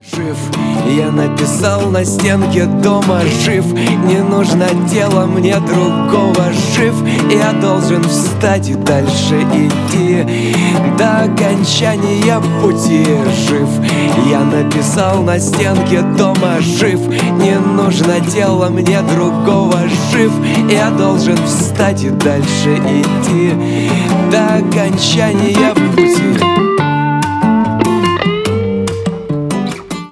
гитара
спокойные
акустика